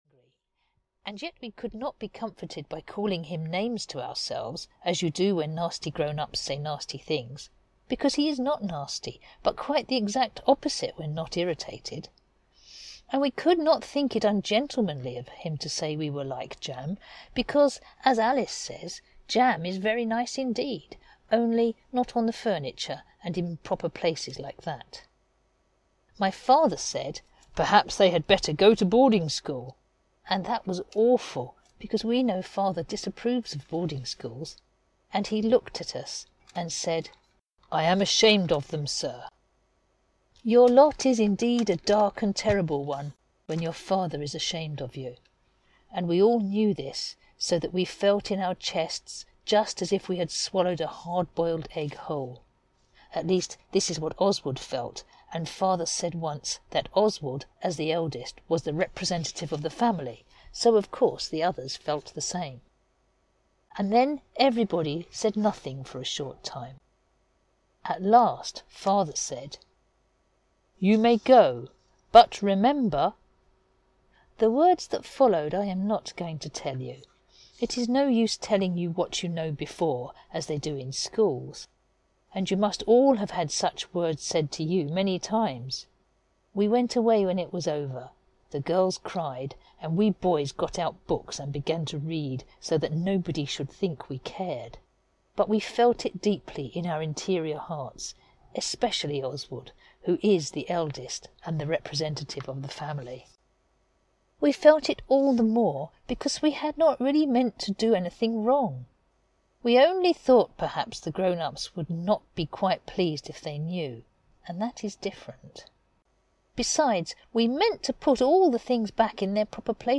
The Wouldbegoods (EN) audiokniha
Ukázka z knihy